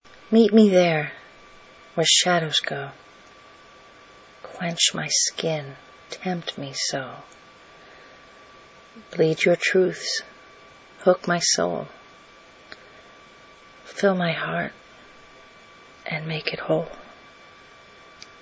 The following micropoetry is based on the Wed 7/23/14 fieryverse poetry prompt challenge as part of Spoken Word Wednesday.